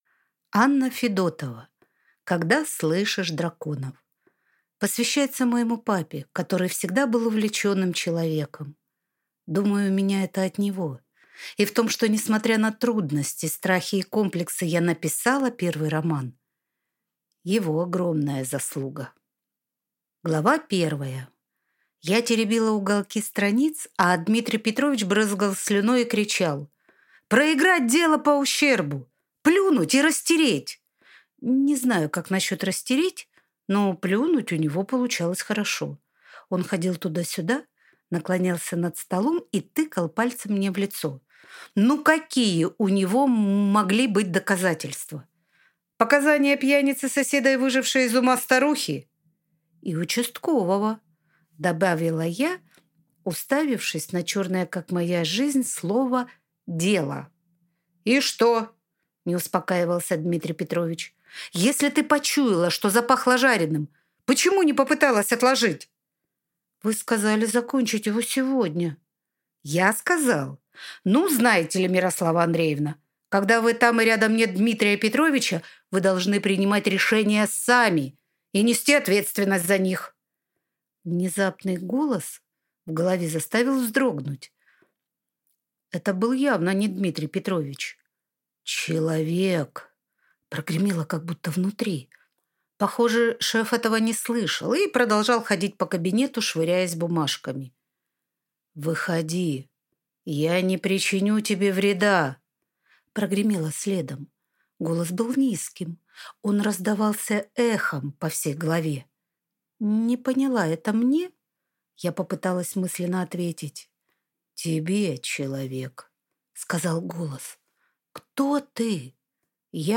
Аудиокнига Когда слышишь драконов | Библиотека аудиокниг